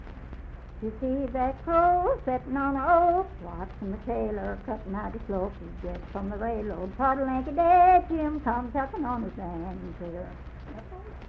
Crow Sitting On an Oak - West Virginia Folk Music | WVU Libraries
Unaccompanied vocal music
Voice (sung)